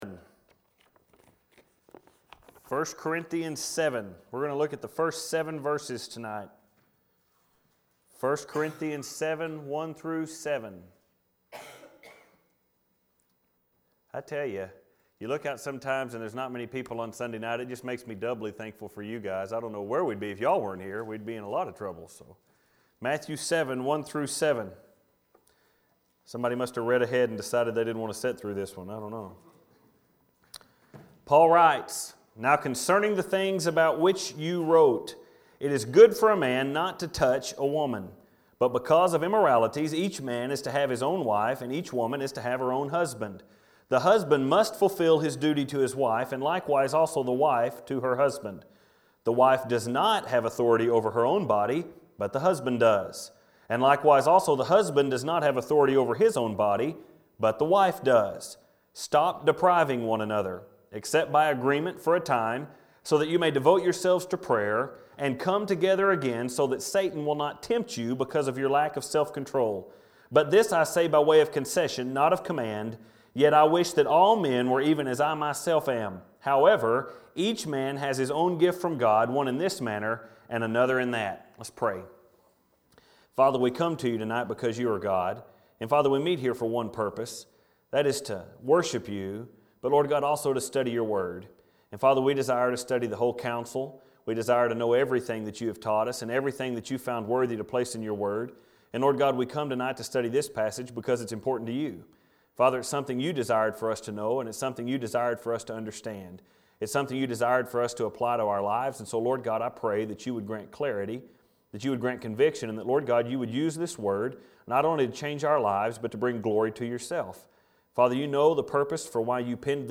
1st Corinthians Stop Depriving One Another – Part 2 - First Baptist Church Spur, Texas
Filed Under: Sermons Tagged With: Corinthians